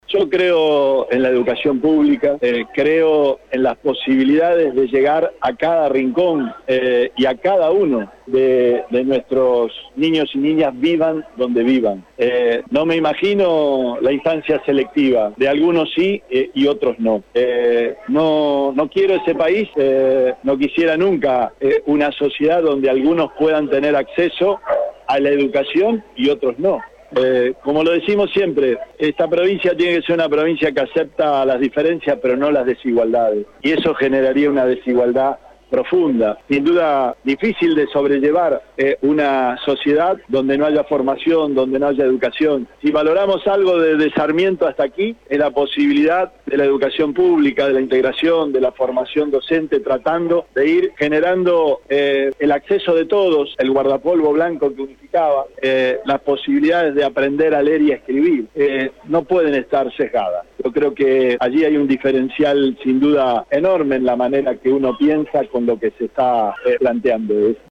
Desde allí, Perotti fue consultado por la prensa sobre la propuesta de campaña de Javier Milei, el economista libertario que obtuvo el 30% de los sufragios en las PASO, y que asegura que, de ser presidente, eliminará el sistema de educación pública para pasar a un programa de vouchers que subsidien la educación de la persona que lo necesita, ahorrándose así miles de millones destinados actualmente.